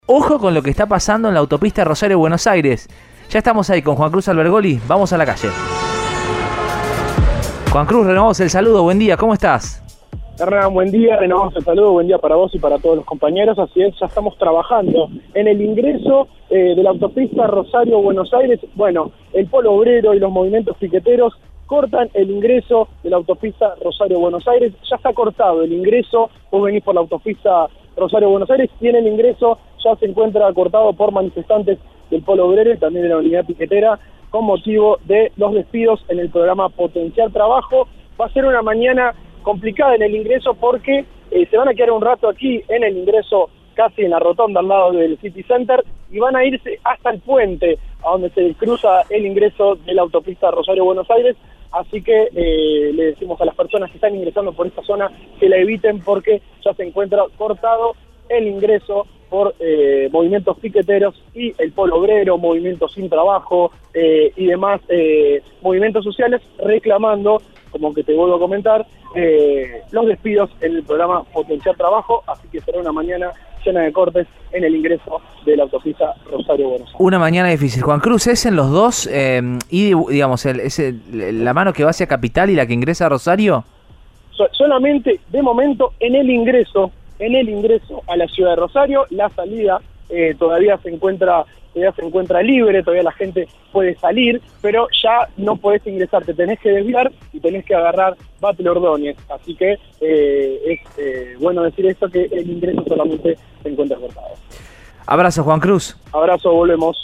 Protestas